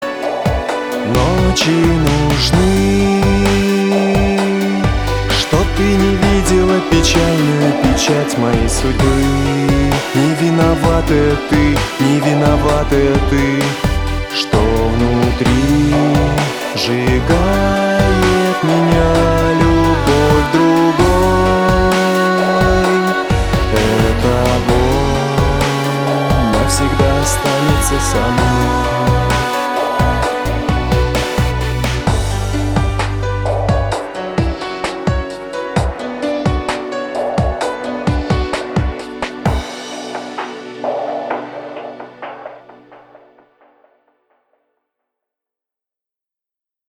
• Качество: 320, Stereo
Песня о любви